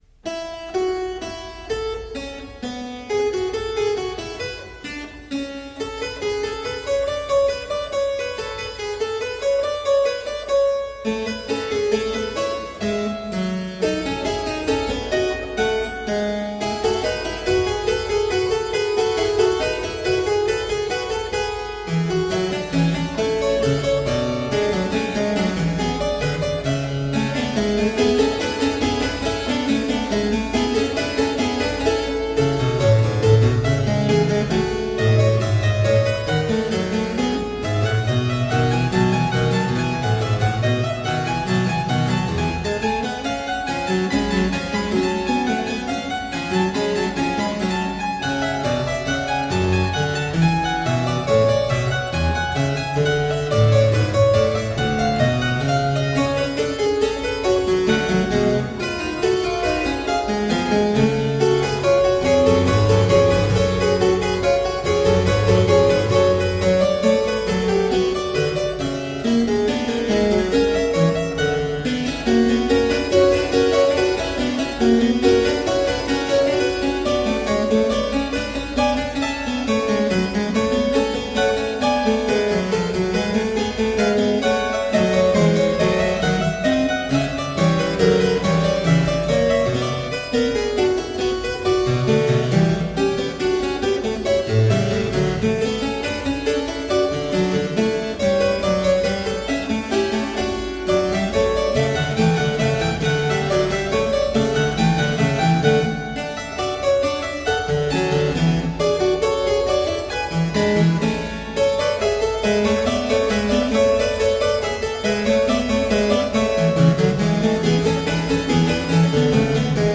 Solo harpsichord music
Tagged as: Classical, Baroque, Instrumental Classical
Harpsichord